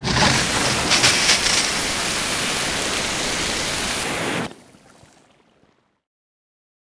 hose.wav